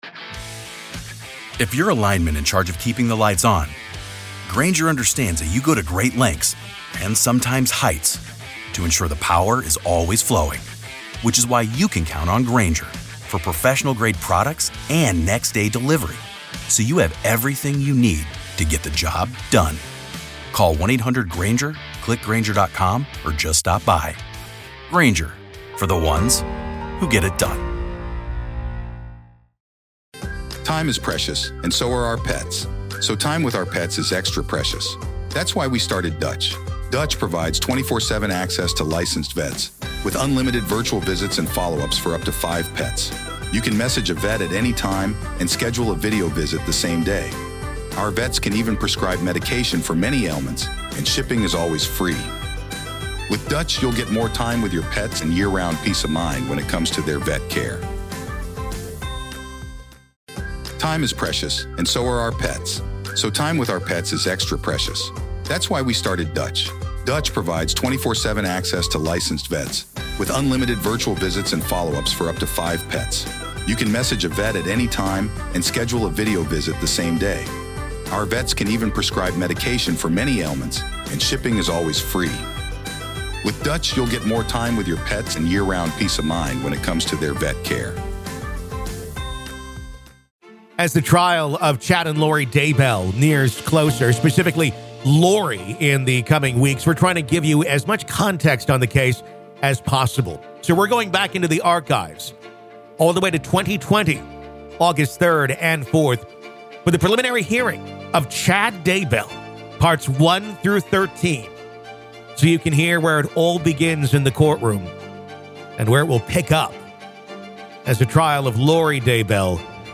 Listen To The Full Preliminary Hearing Of Chad Daybell, Part 12
This is the complete preliminary hearing of Chad Daybell, originally recorded August 3rd and 4th of 2020.